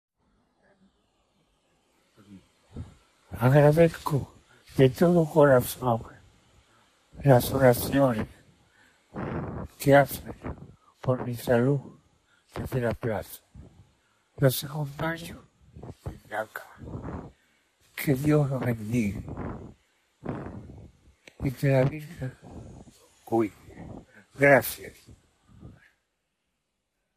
Per Audiobotschaft hat er sich mit schwacher Stimme zu Wort gemeldet.
In einer kurzen, vorher aufgezeichneten Audio-Botschaft aus der Gemelli-Klinik dankte der 88-Jährige den Menschen auf dem Petersplatz für ihre Gebete.
Papst ringt in Audiobotschaft nach Luft
Die wenigen Sätze, bei denen Franziskus hörbar nach Luft rang und eine sehr schwache Stimme hatte, wurden vom Vatikan auf den Petersplatz übertragen.